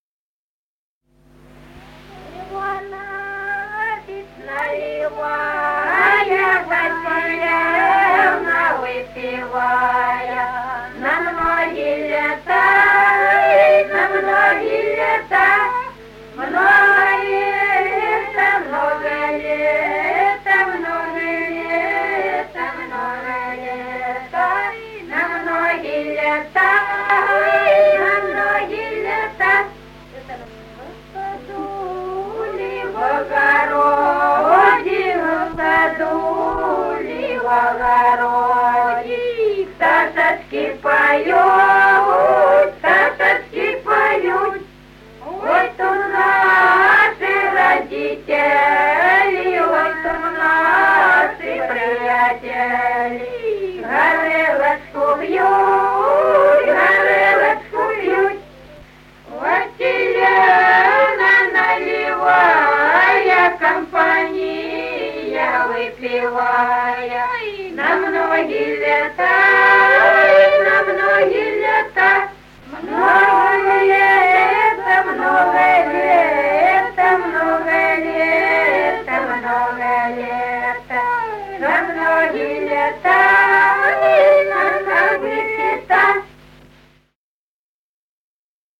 Народные песни Стародубского района «Иванович наливая», застольная «банкетная» песня.
с. Курковичи.